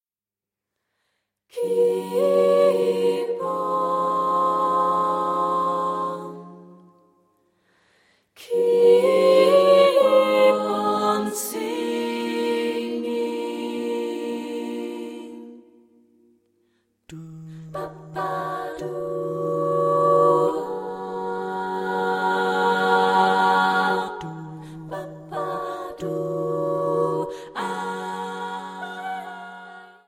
dieses A-cappella–Ensembles ausmacht